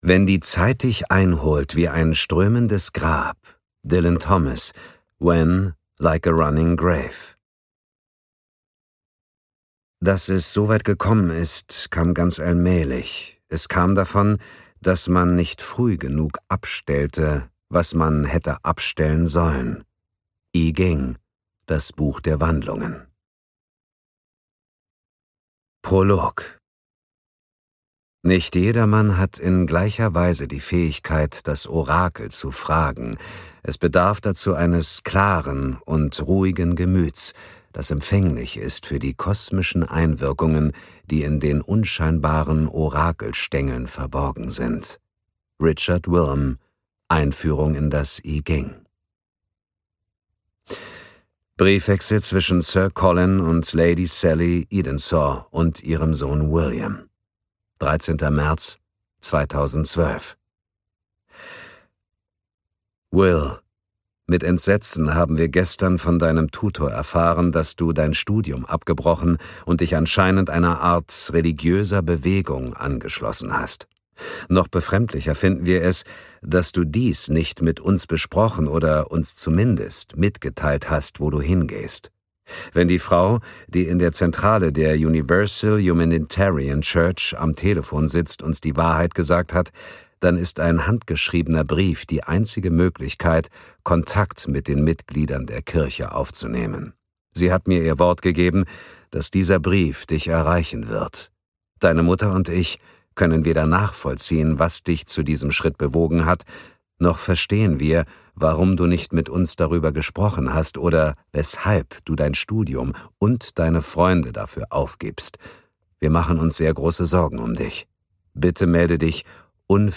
Ukázka z knihy
• InterpretDietmar Wunder